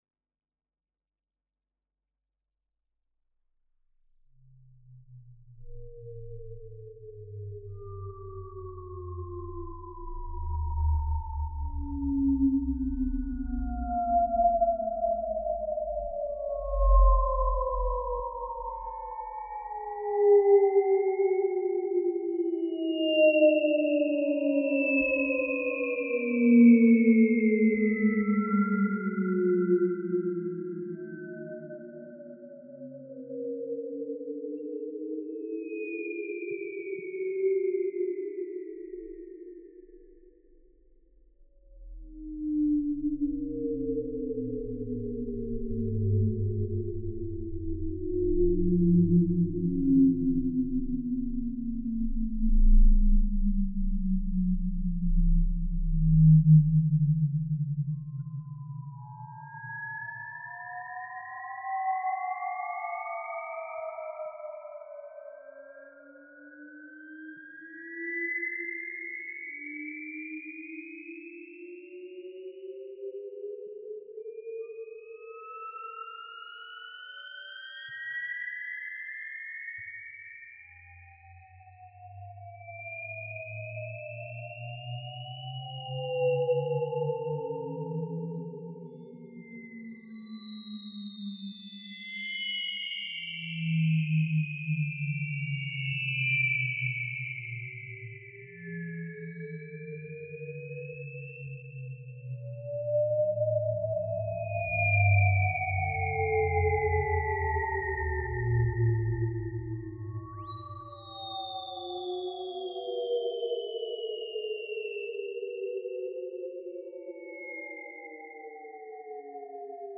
Done with a very under-rated british synth